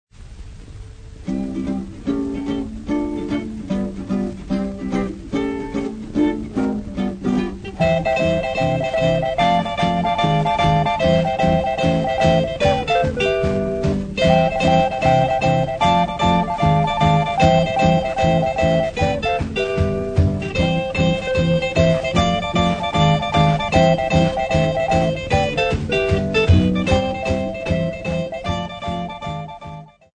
Popular music--Africa
Dance music
Field recordings
sound recording-musical
Dance performed on various instruments